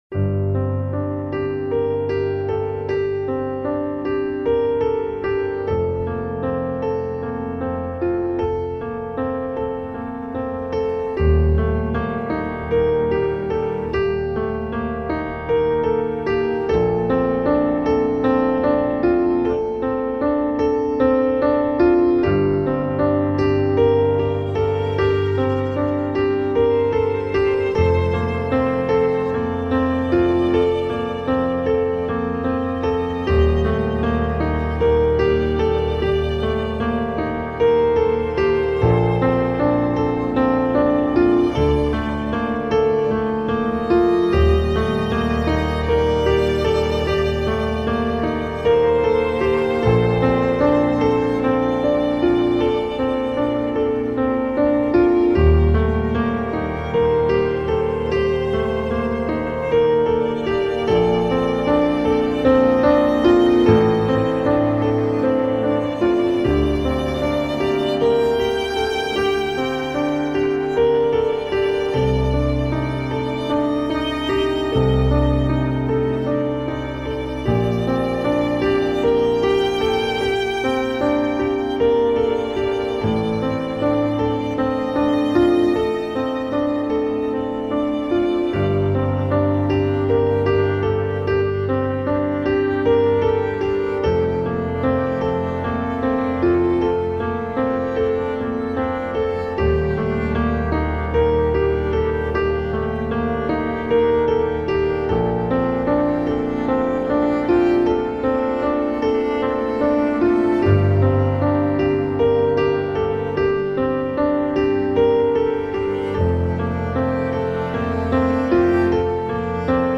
موسیقی متن
موسیقی بیکلام